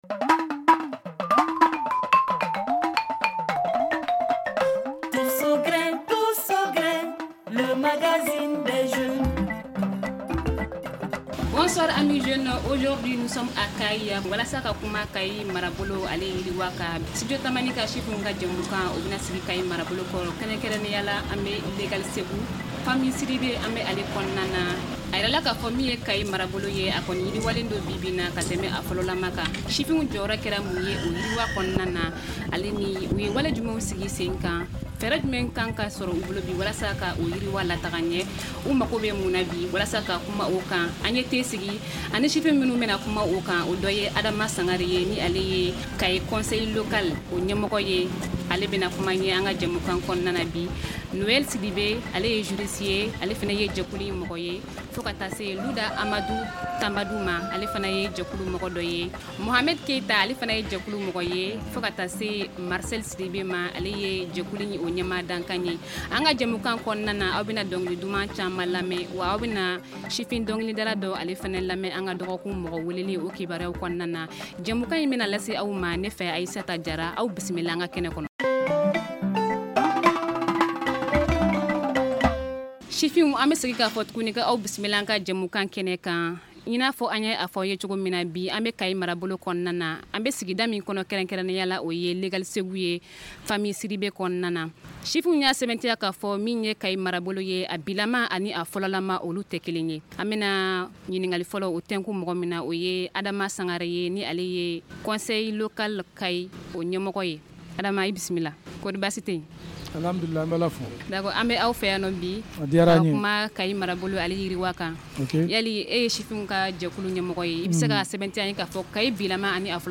L’équipe Tous au grin était à Kayes avec ses invités qui sont du conseil local et conseil communal de Kayes.